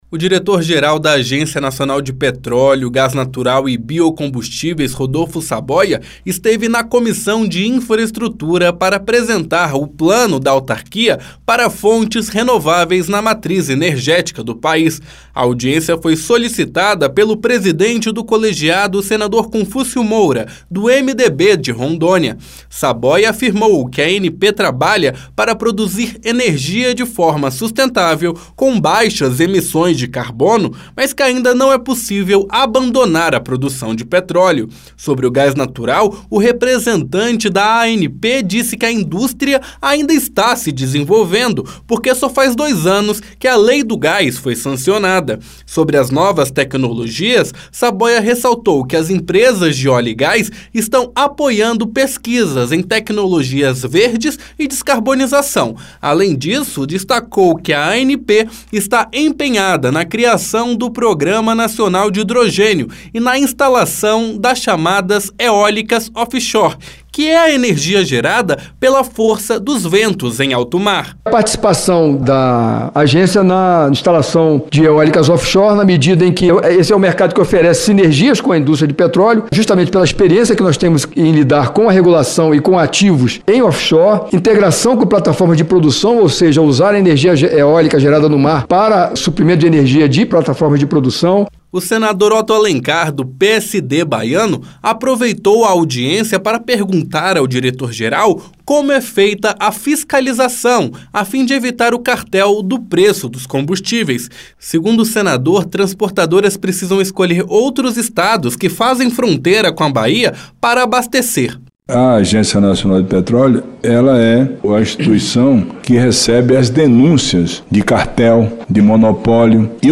Em audiência na Comissão de Serviços de  Infraestrutura (CI) nesta terça-feira (03), o diretor-geral da Agência Nacional do Petróleo, Gás Natural e Biocombustíveis (ANP), Rodolfo Saboia, apresentou o plano de atuação da agência na introdução de fontes de energia renovável no país.